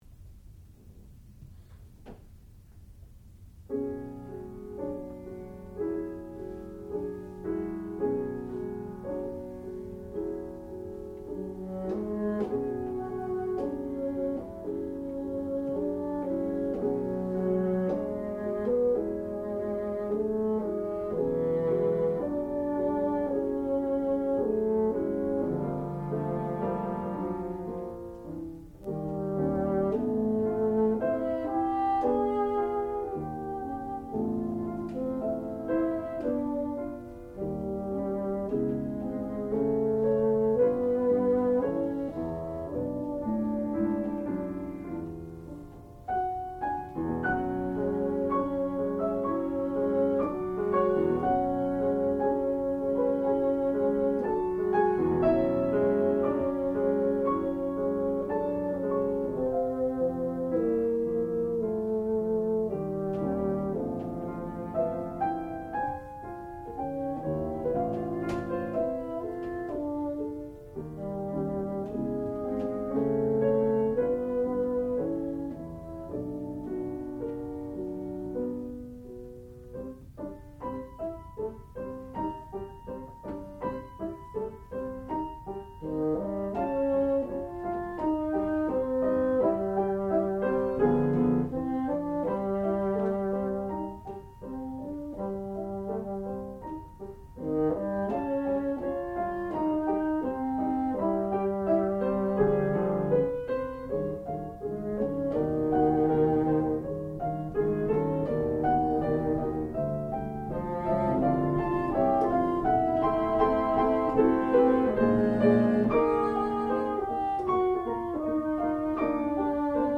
classical music
piano
bassoon
Junior Recital